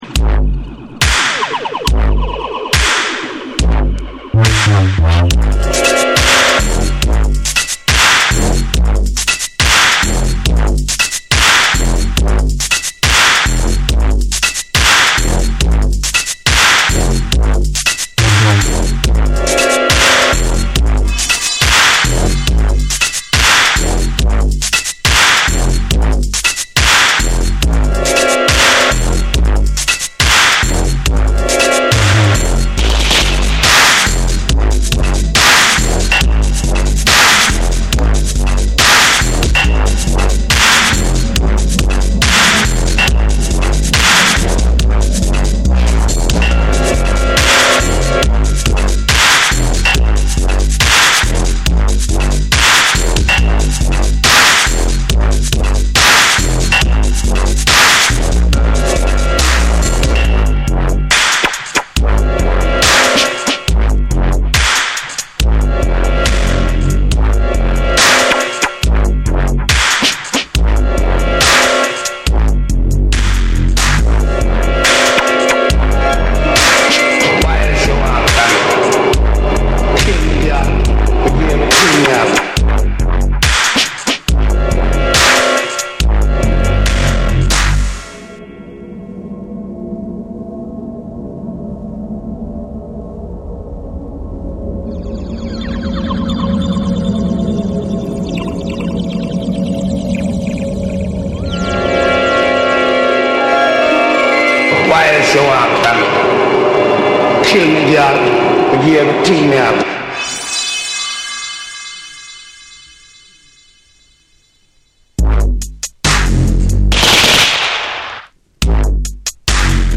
荒々しく歪んだベースとタイトなビートがぶつかり合う、攻撃性の高いフロア直撃型ダブステップ
BREAKBEATS / DUBSTEP